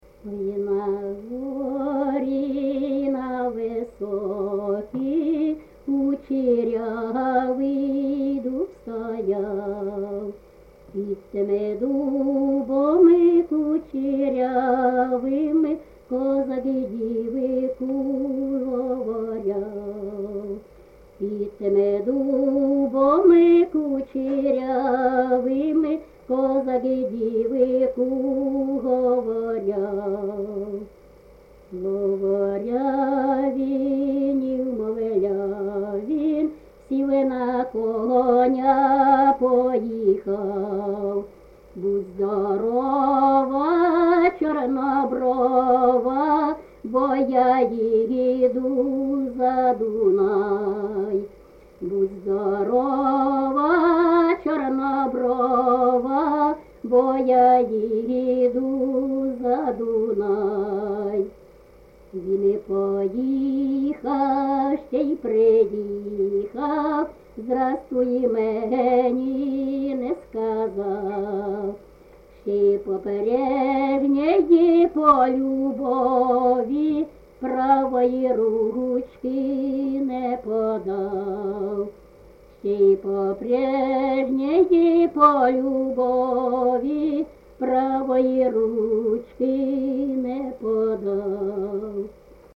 ЖанрПісні з особистого та родинного життя, Козацькі
Місце записус-ще Михайлівське, Сумський район, Сумська обл., Україна, Слобожанщина